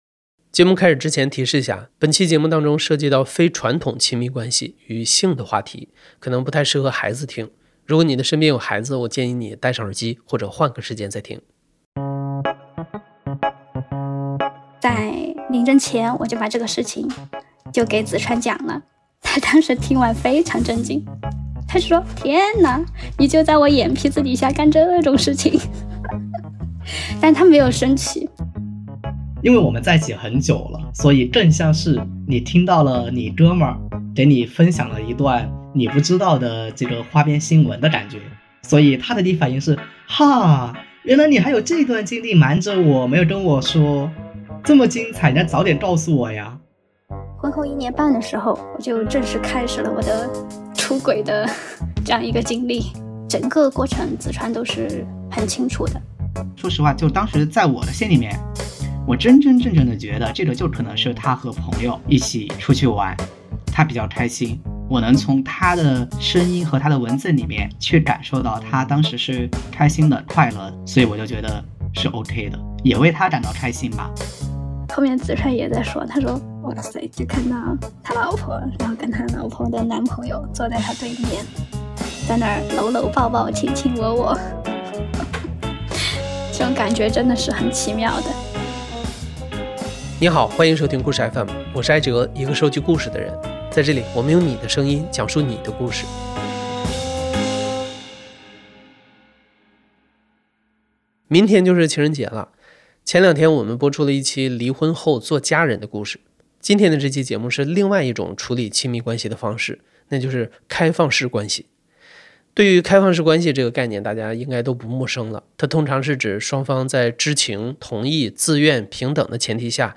*提示一下，为了保护讲述者的隐私，本期节目我们做了变声处理。
故事FM 是一档亲历者自述的声音节目。